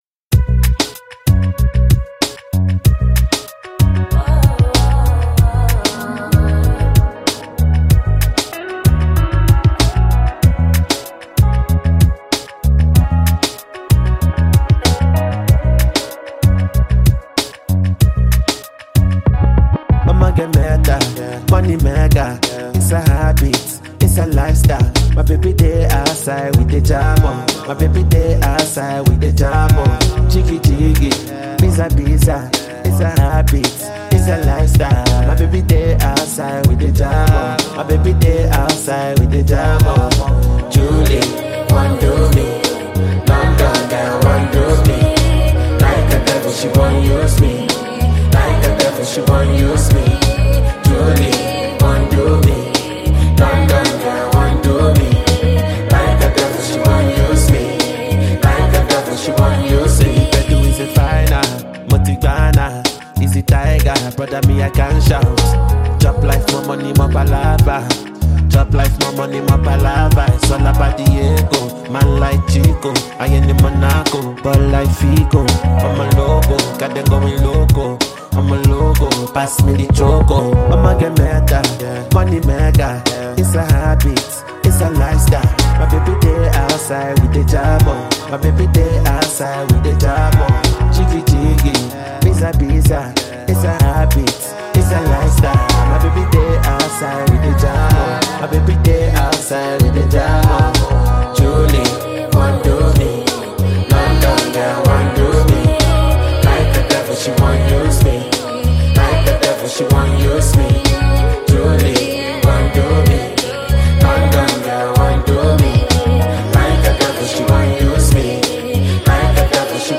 With its irresistible vibe and soulful appeal
Afrobeats